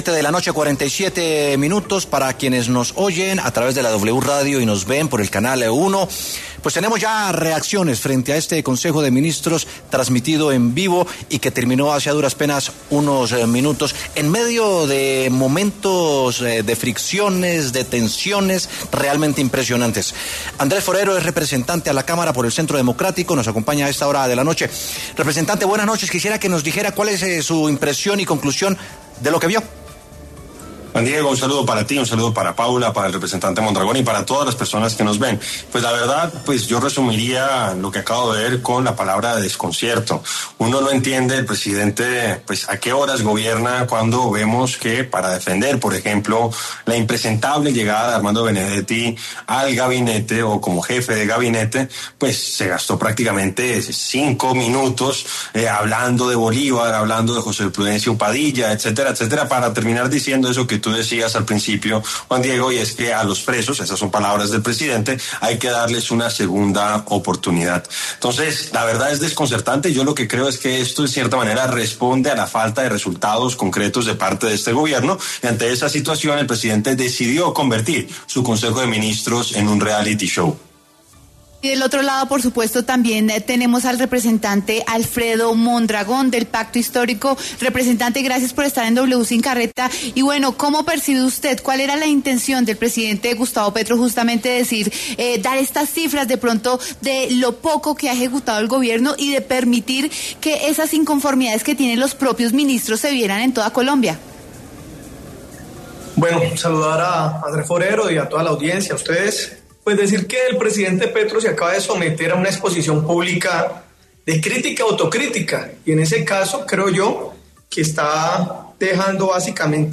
Por esta razón, y para analizar el Consejo, hablaron en W Sin Carreta el representante Andrés Forero, del Centro Democrático, y Alfredo Mondragón, del Pacto Histórico.